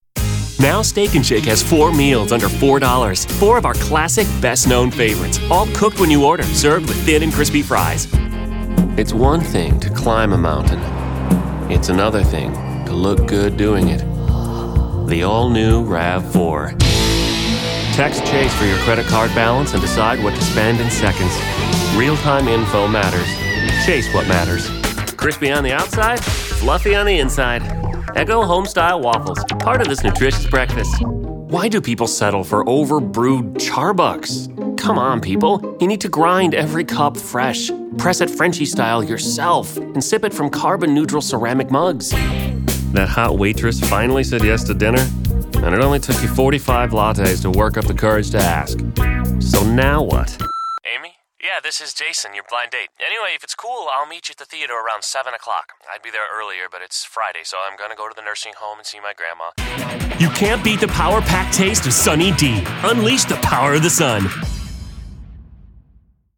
Male
English (North American)
Male Voice Over Talent